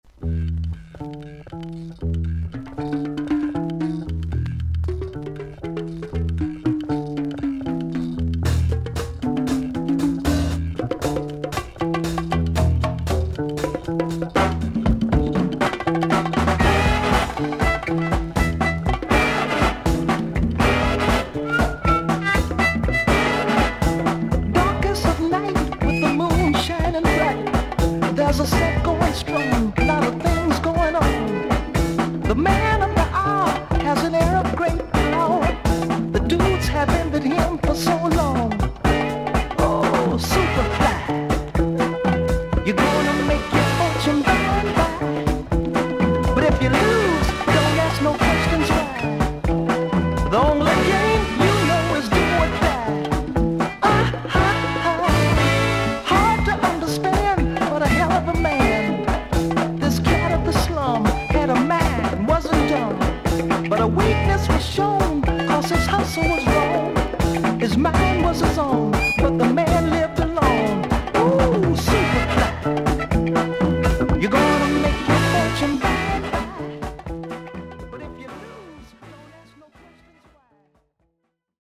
ソウル・シンガー/ソング・ライター/ギタリスト。
VG++〜VG+ 少々軽いパチノイズの箇所あり。クリアな音です。